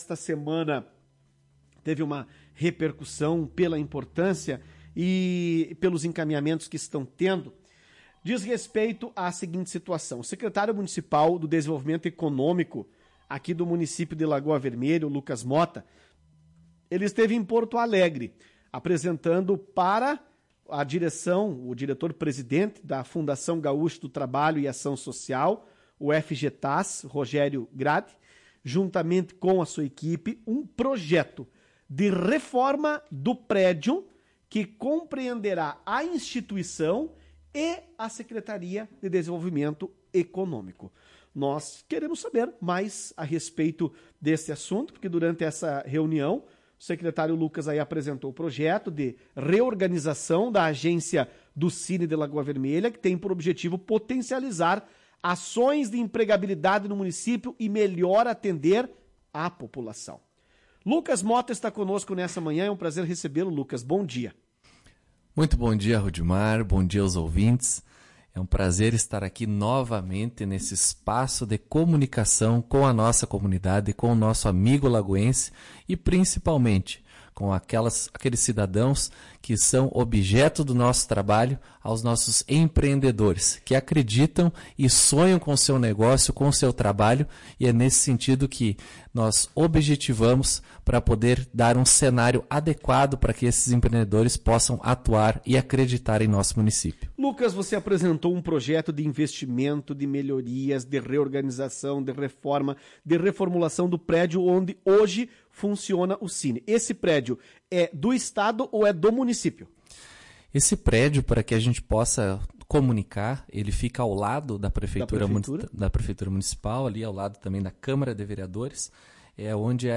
Na manhã desta quinta-feira, 24 de fevereiro, o gestor concedeu entrevista à Tua Rádio.